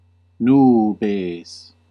Ääntäminen
US : IPA : [swɔrːm]